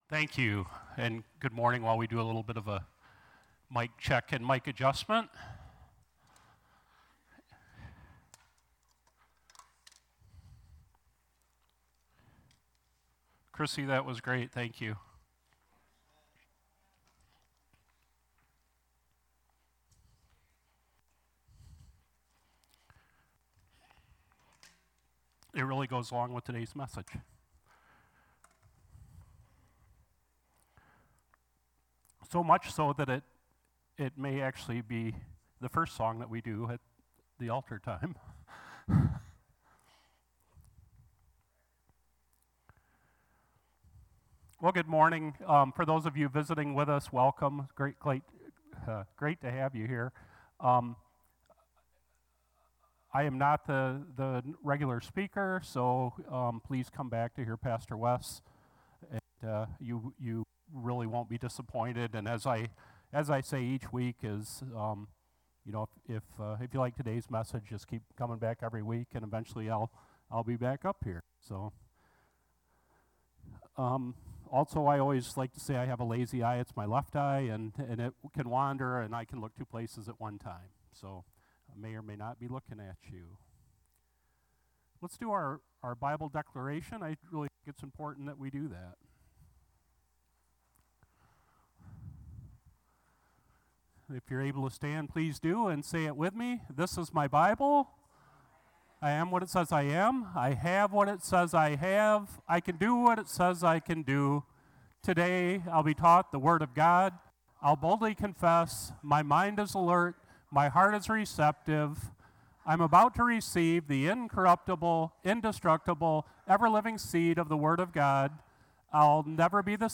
Sermon-7-21-24.mp3